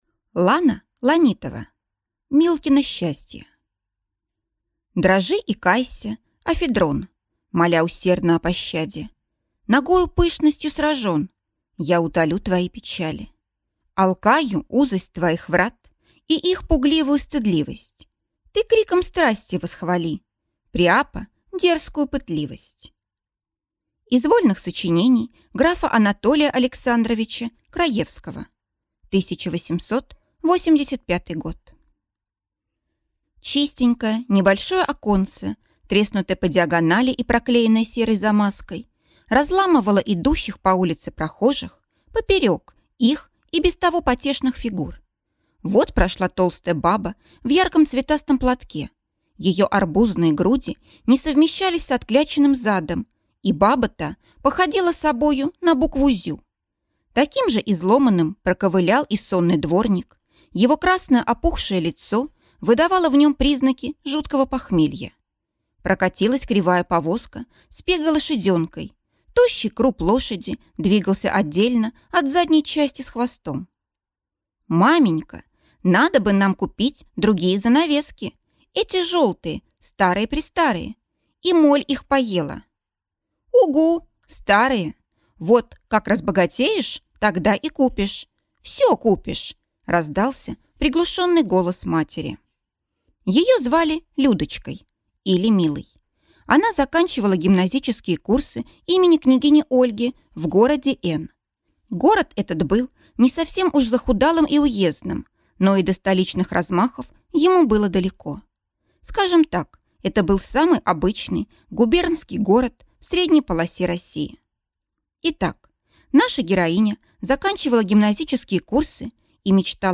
Аудиокнига Милкино счастье | Библиотека аудиокниг